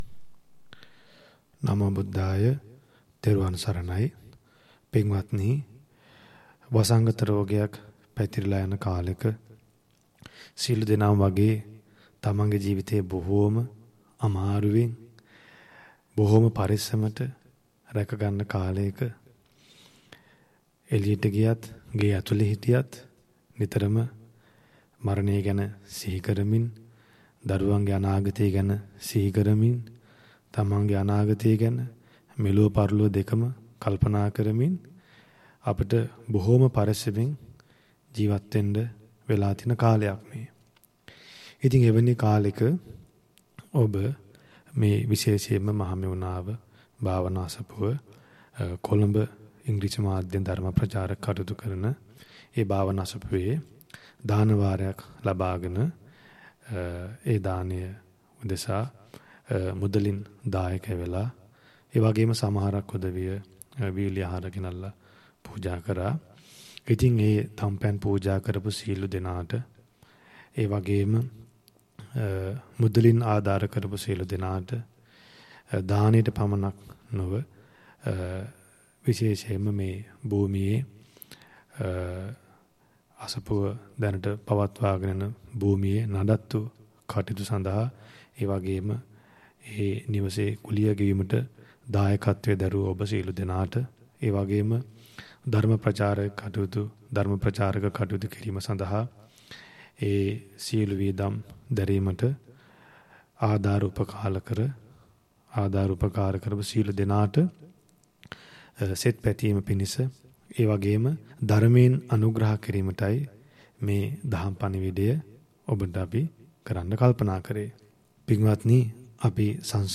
Dana Sermons